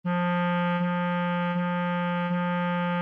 Piano (Corda percutida) | Orquestra de cARTón (ODE5)